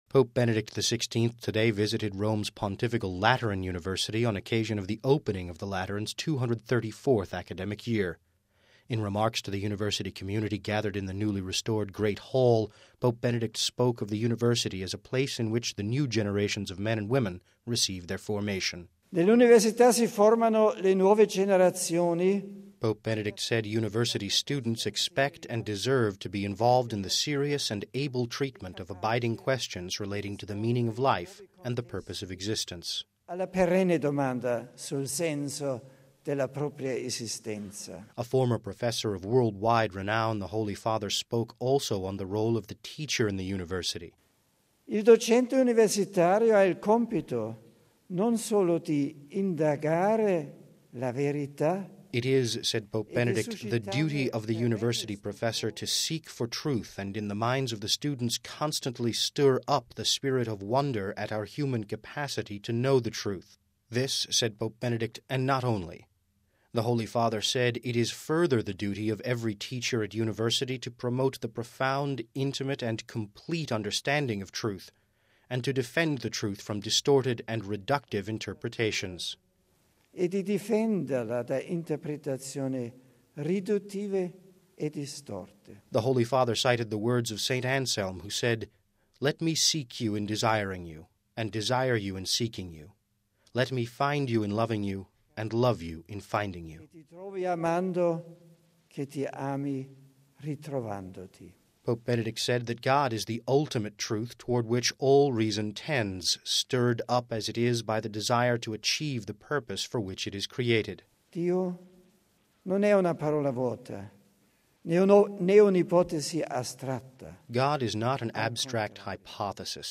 (21 Oct 06 - RV) Pope Benedict XVI met with an enormous crowd of students and alumni at Rome’s Pontifical Lateran University today on occasion of the opening of the Lateran’s 234 th academic year.